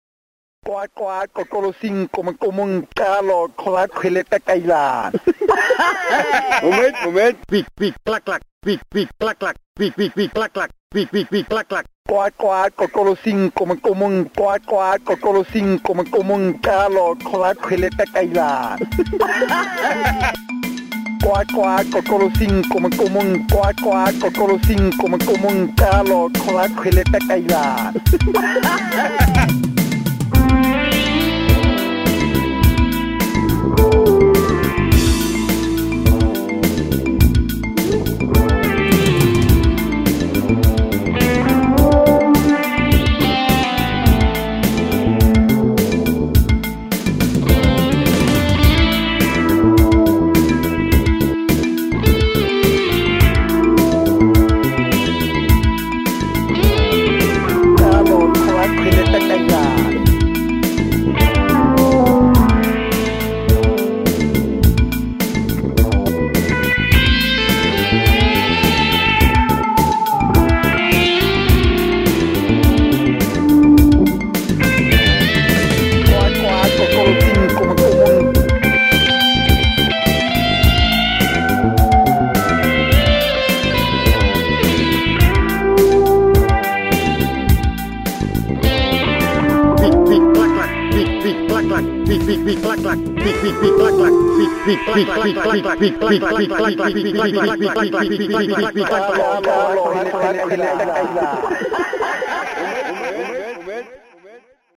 Eigentlich passiert ja im Backing nicht so viel, aber, oder vielleicht auch gerade deshalb, wirkt der Groove - jedenfalls auf mich - ganz schön hypnotisch.
Aufgenommen mit meiner Tokai Junior, dem Womanizer und etwas Hall und einem kleinen Filter in Logic.